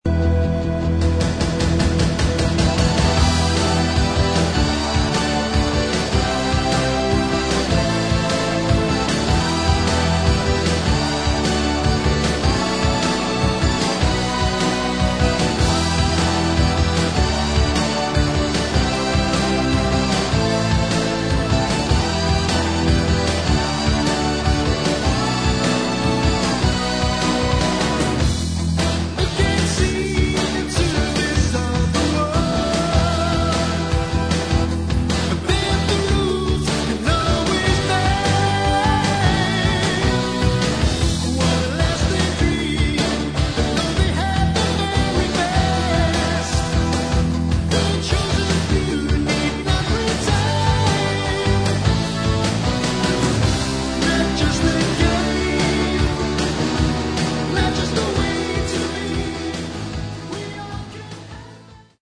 Metal
New Wave Of British Heavy Metal